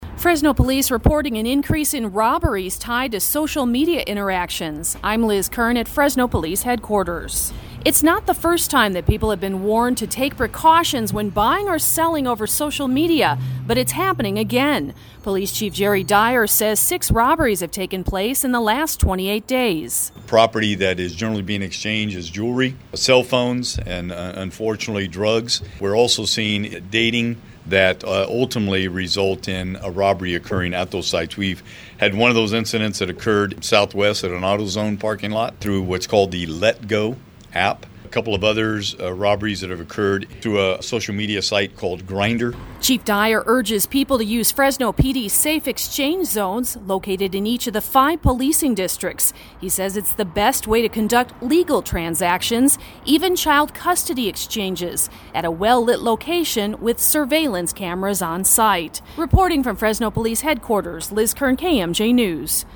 During Wednesday’s Crime View report at Fresno Police Headquarters, Police Chief Jerry Dyer said six robberies have taken place in the last 28 days.